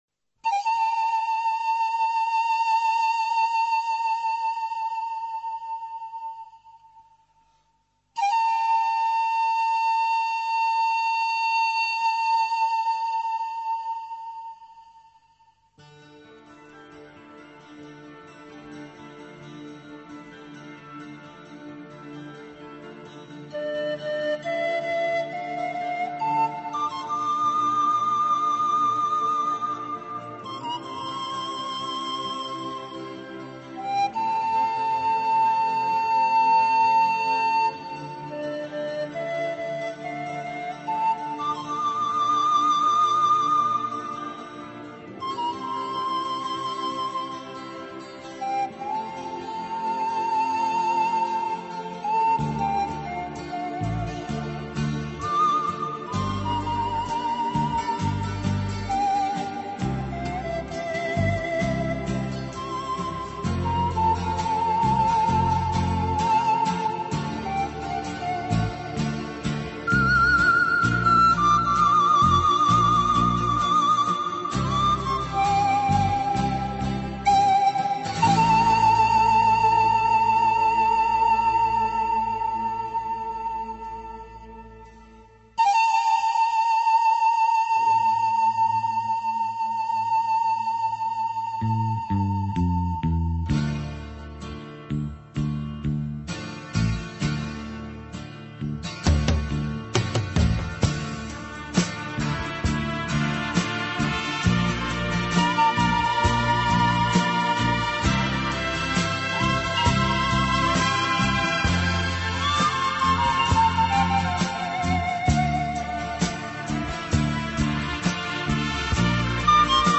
flejta_Odinokij_pastuh__.mp3